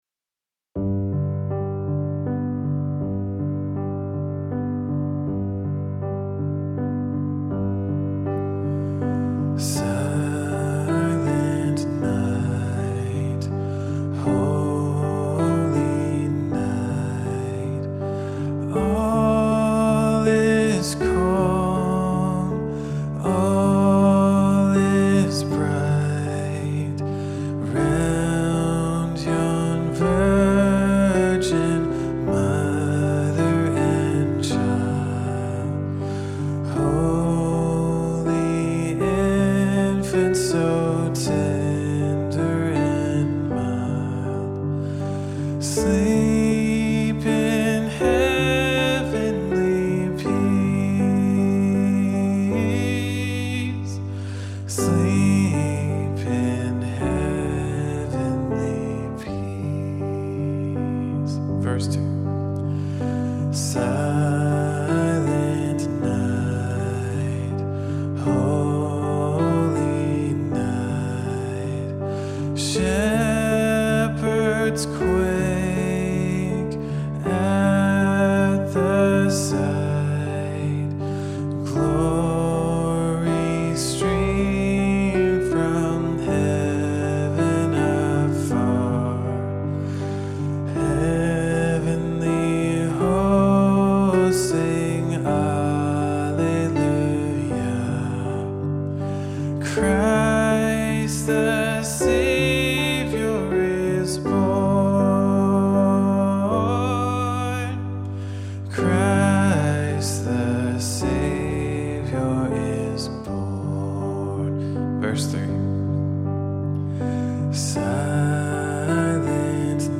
silent-night-singalong-guide-w_vocal.mp3